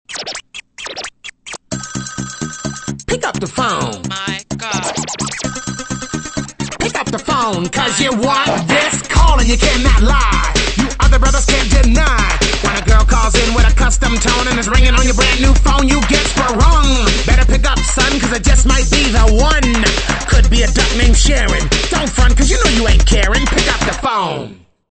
Categoria Effetti Sonori